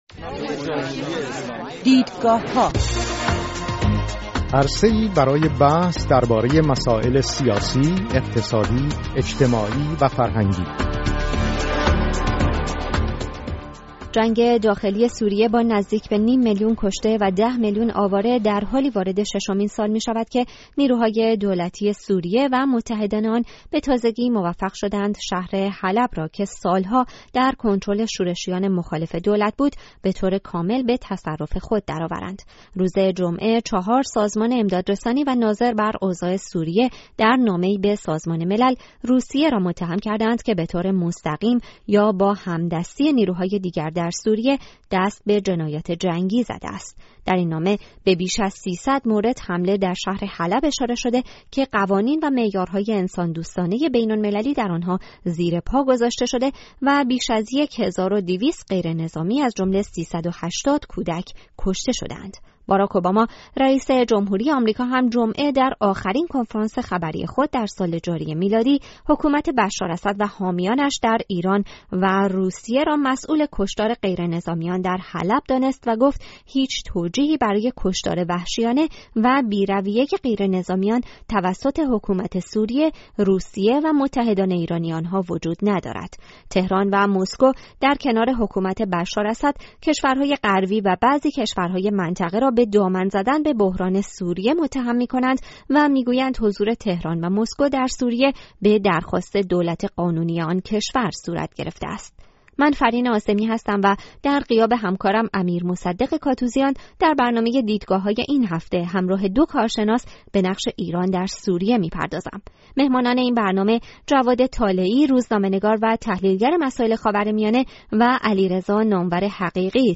در برنامه دیدگاه‌های این هفته همراه دو کارشناس به نقش ایران در سوریه می‌پردازیم.